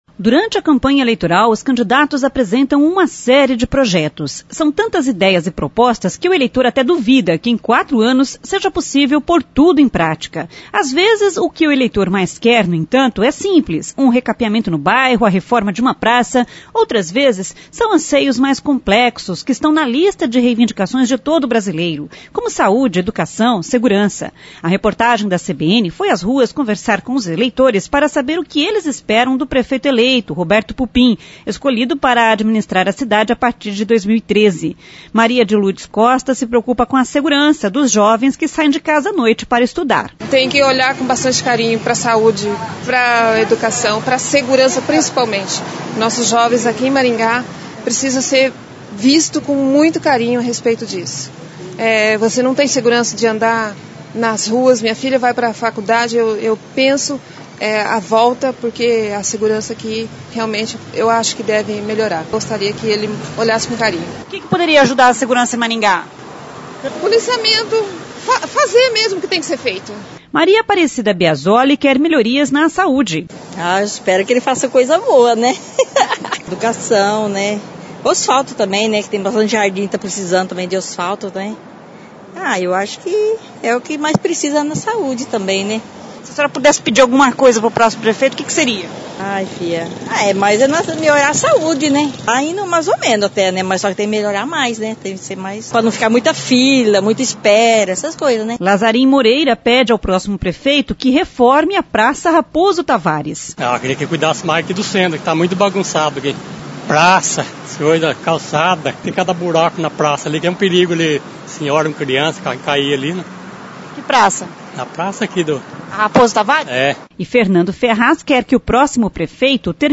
A reportagem da CBN foi às ruas ouvir eleitores. E a expectativa é principalmente em relação à saúde, educação e segurança